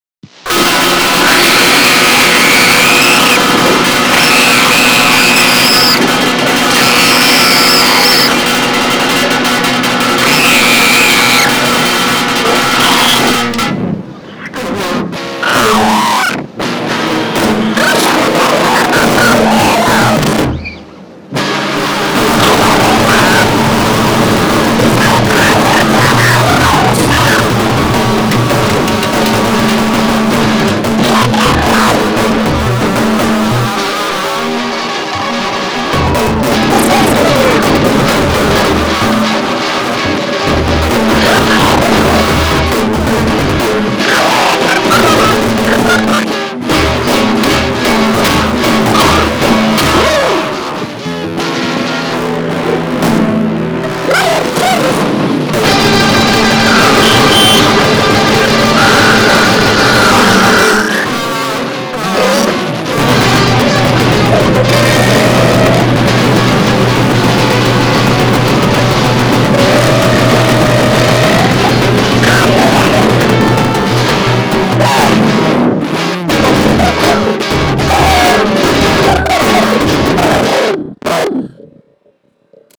noise,